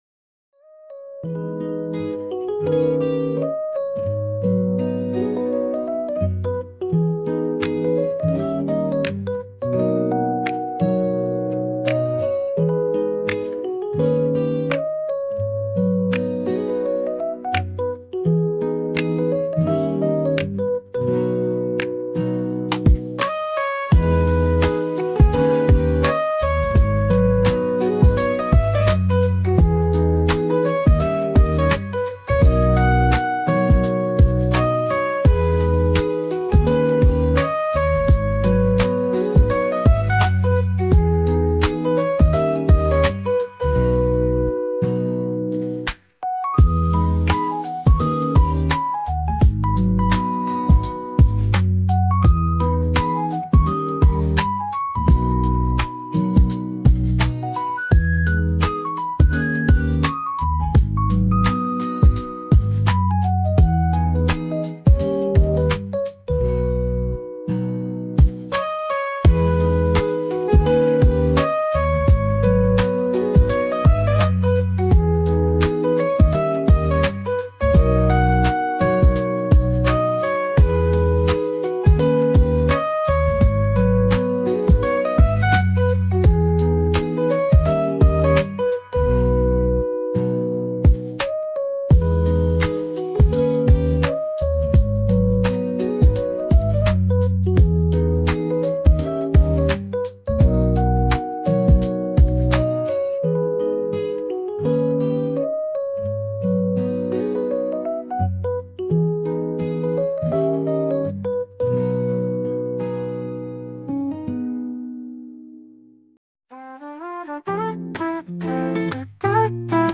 lo-fi music